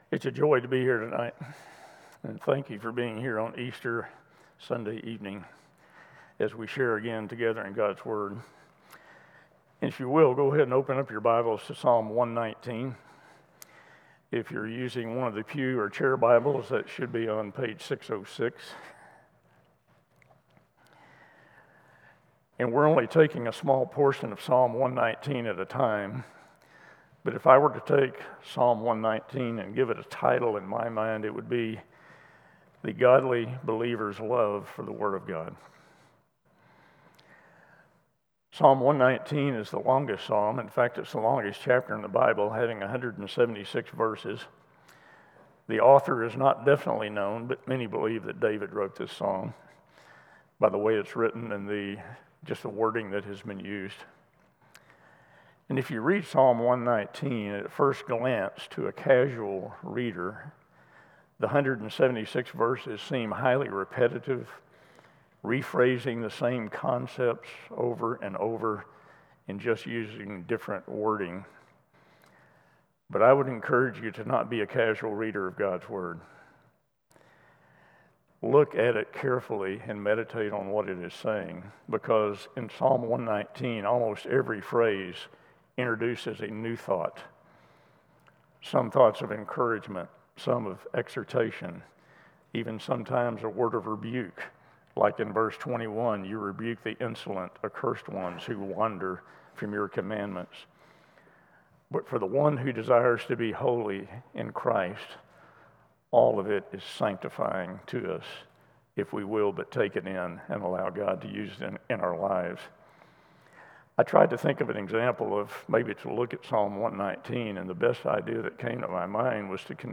CCBC Sermons Psalm 119:9-16 Apr 20 2025 | 00:29:38 1x 00:00 / 00:29:38 Subscribe Share Apple Podcasts Spotify Overcast RSS Feed Share Link Embed